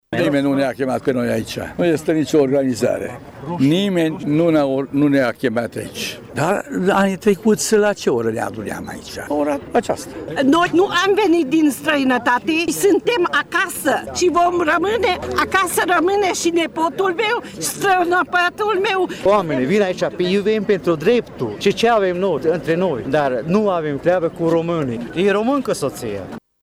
Circa 450 de persoane s-au strâns, de la ora 16,00, la Monumentul Secuilor martiri din Tîrgu-Mureș, pentru a comemora Ziua Libertății Secuiești.
Cei cu care am stat de vorbă susțin că nimeni nu i-a convocat, ei au venit spontan, ca și anul trecut: